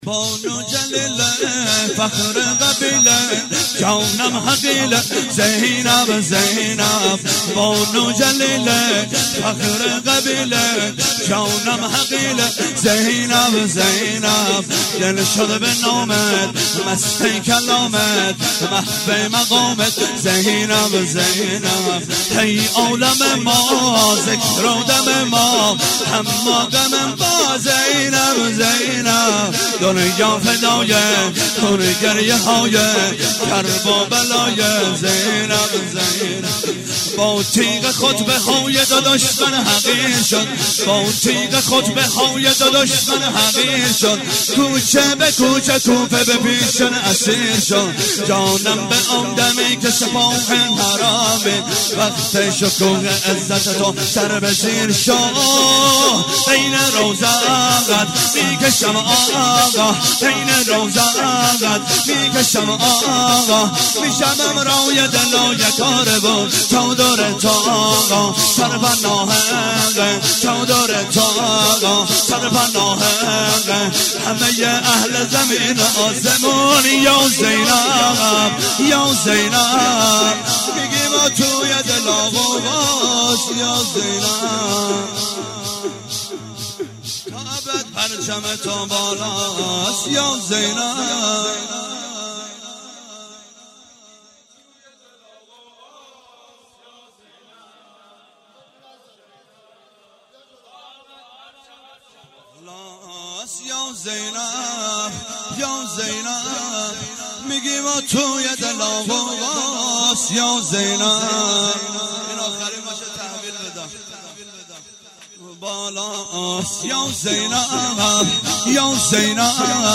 هیئت زواراباالمهدی(ع) بابلسر - شور - بانوجلیله-فخرقبیله
توسل هفتگی 12 مرداد 97